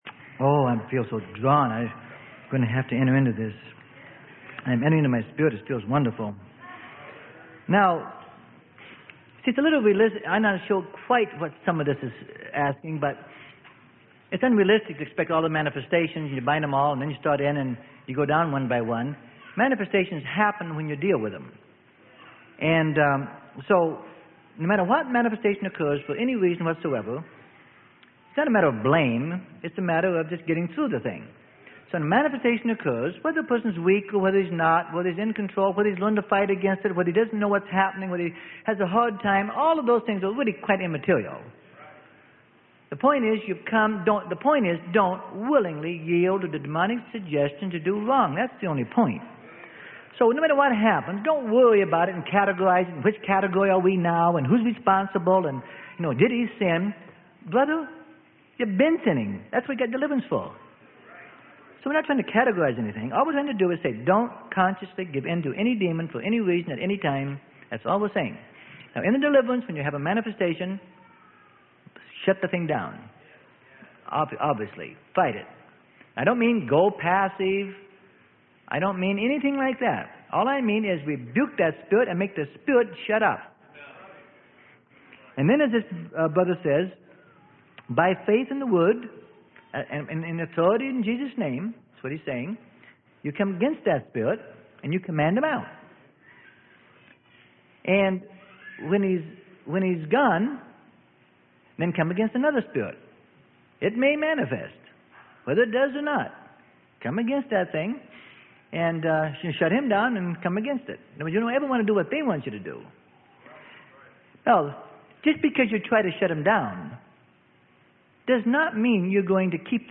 Sermon: QUESTIONS AND ANSWERS ABOUT DELIVERANCE PART 2 OF 2.